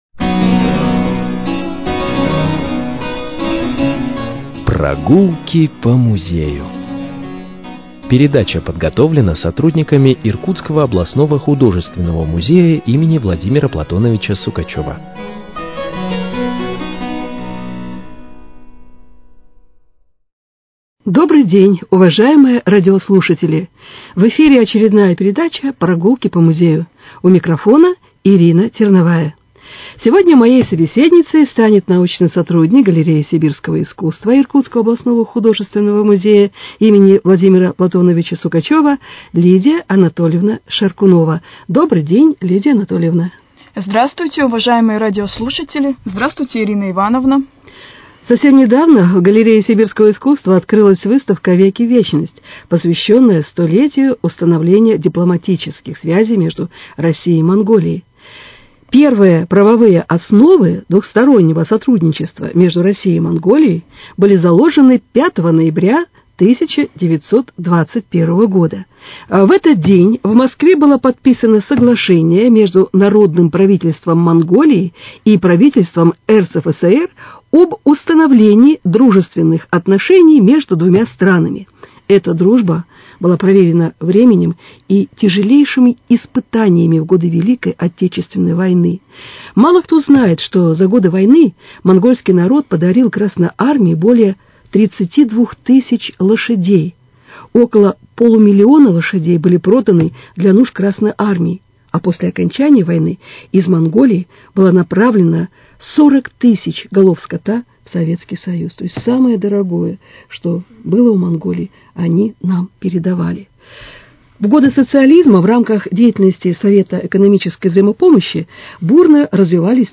Вашему вниманию беседа с научным сотрудником